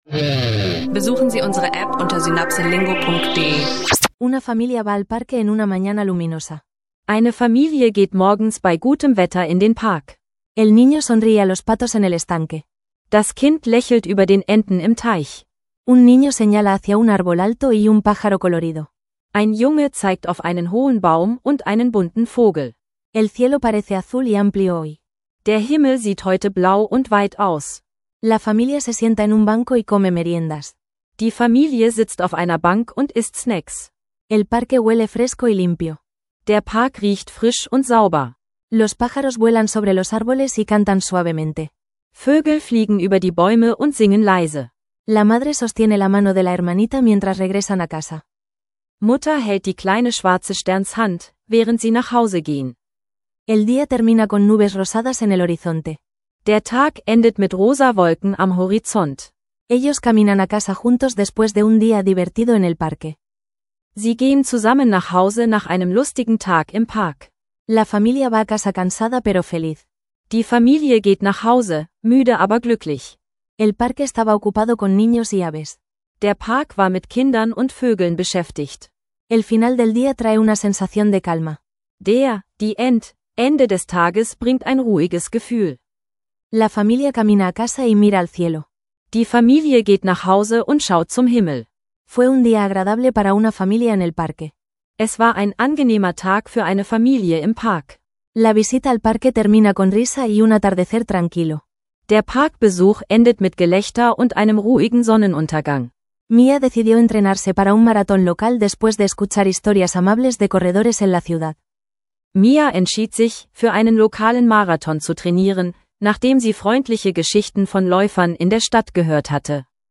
Zwei leicht verständliche Spanisch-Lektionen: Ein Tag im Park und Marathon-Training – übe Spanisch lernen online mit SynapseLingo
und Trainingsbeginn für einen Marathon. Mit einfachen Dialogen,